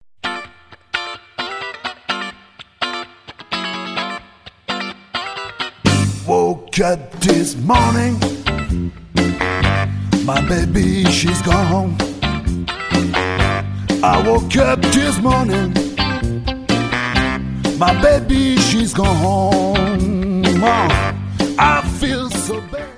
...D'inspiration resolument rock
Un album à taper du pied sans modération...